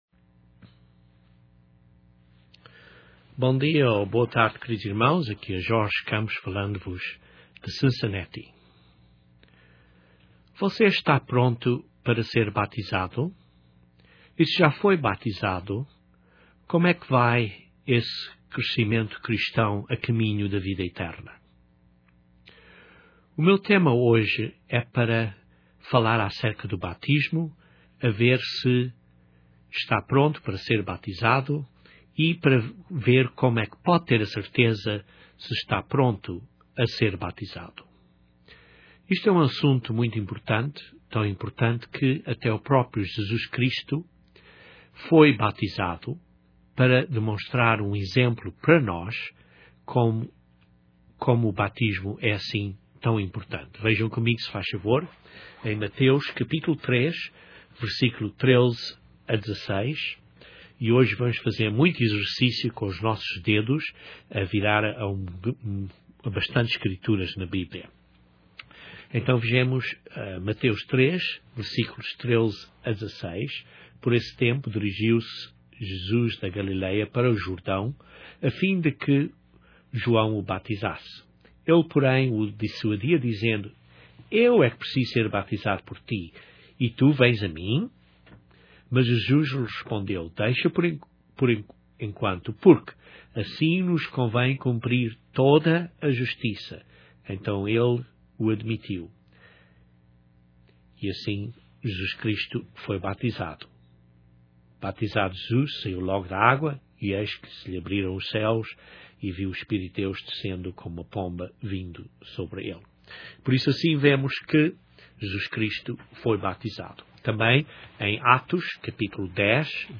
Estes dois sermões foram dados para o ajudar a preparar-se para o batismo Cristão. Este primeiro sermão é para o ajudar a preparar-se para o batismo.